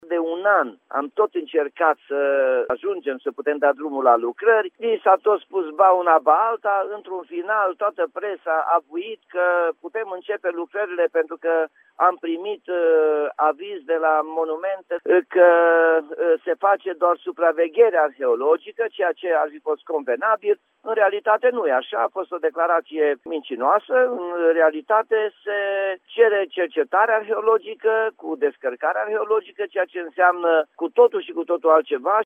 Lucrările de reamenajare a sensului giratoriu din Piaţa Mărăşti din Timişoara ar putea începe abia anul viitor, a anunţat la Radio Timişoara, primarul Nicolae Robu.
Nicolae-Robu-1.mp3